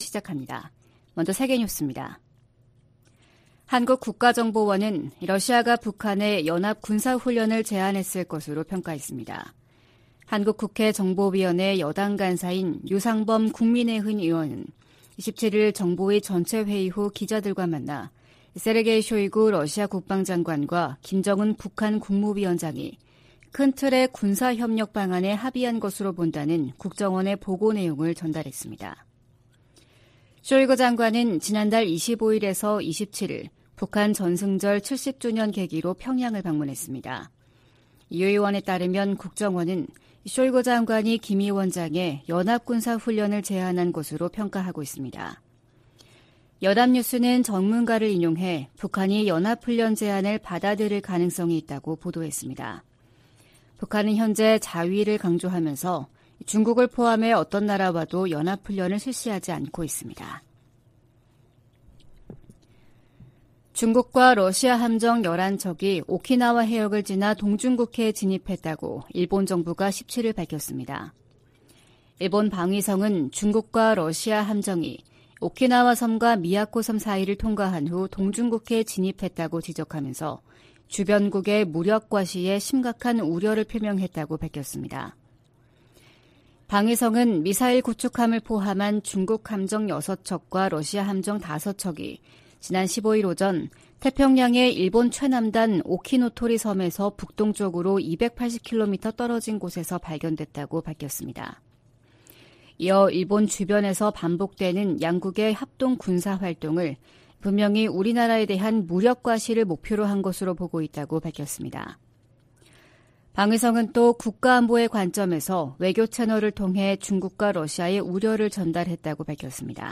VOA 한국어 '출발 뉴스 쇼', 2023년 8월 18일 방송입니다. 백악관은 미한일 정상회의에서 3국 협력을 강화하는 중요한 이니셔티브가 발표될 것이라고 밝혔습니다. 백악관은 자진 월북한 주한미군 병사가 망명을 원한다는 북한의 발표를 신뢰하지 않는다고 밝혔습니다. 미 재무부가 북한-러시아 간 불법 무기거래에 관해 러시아와 슬로바키아, 카자흐스탄 소재 기업 3곳을 제재했습니다.